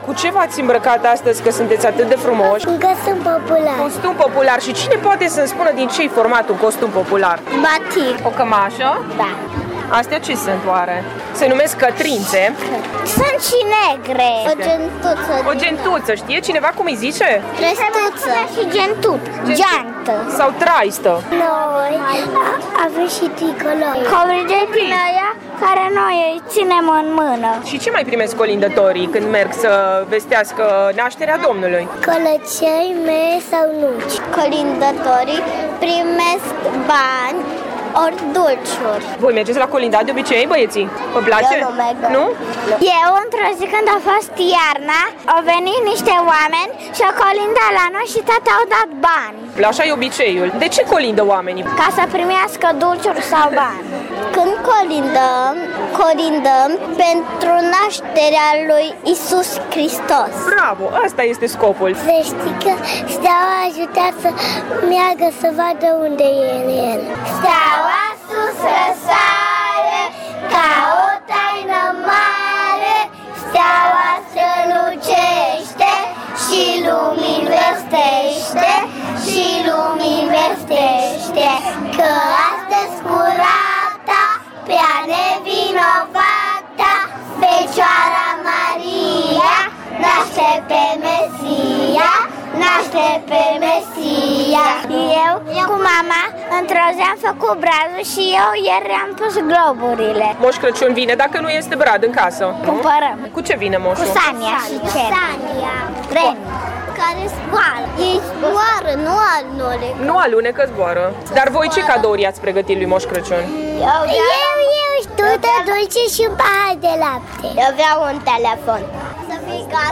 Paricipanți la festivalul de colinde „Colindăm, Doamne colind”, organizat, în fiecare an, de Grădinița Nr. 6 din Târgu Mureș, preșcolarii de la Grădinița „Albinuța” descriu portul popular românesc, colindă și îi pregătesc cadouri lui Moș Crăciun, pe care îl așteaptă cu nerăbdare.